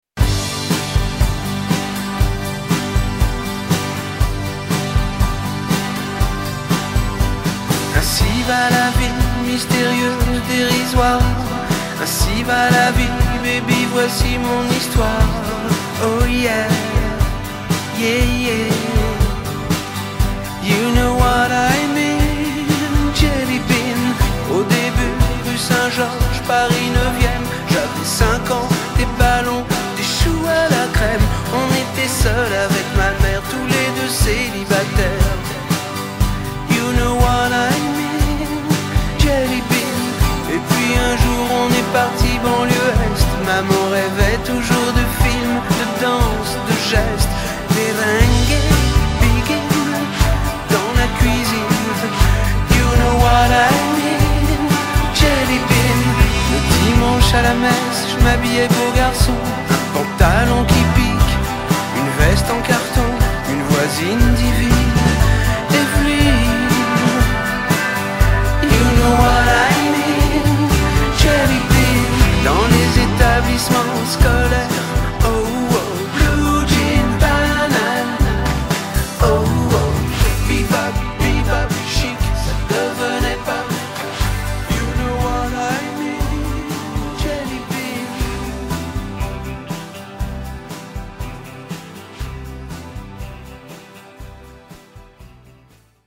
tonalité SOL majeur (et DO)